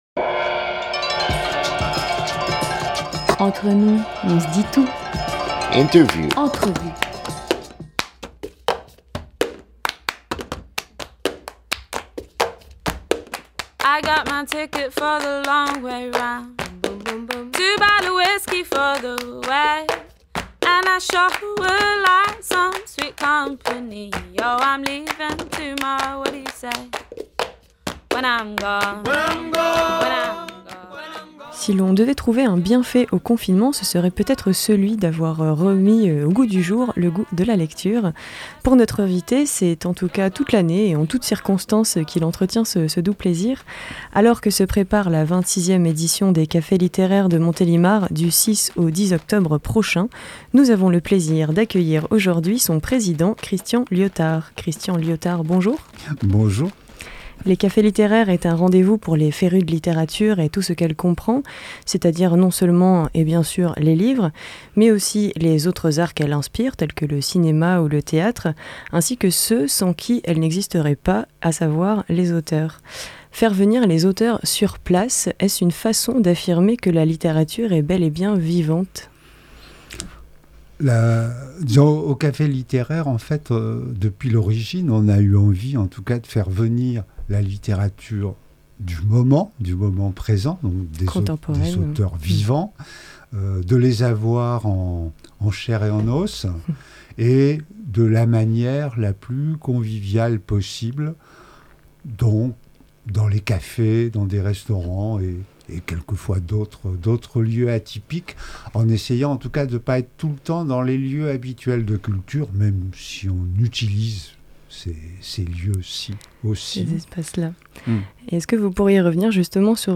20 septembre 2021 8:00 | Interview